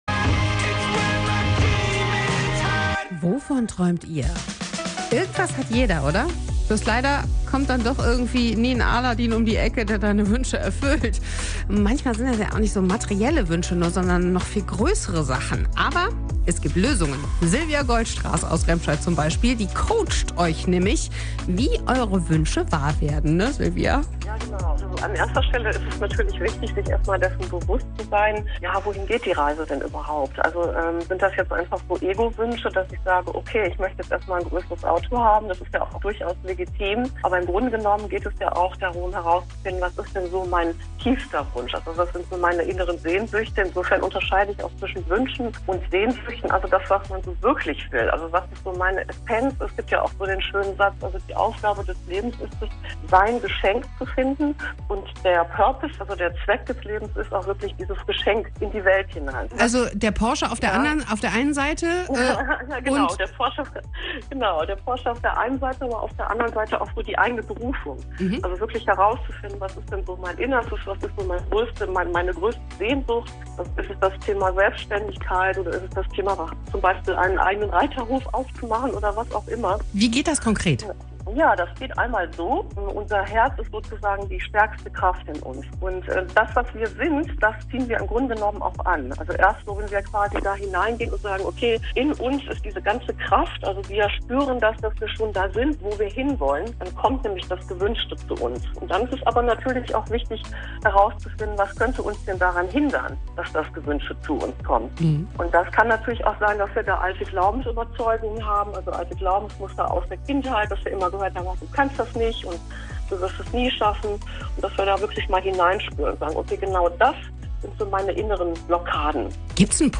Telefoninterview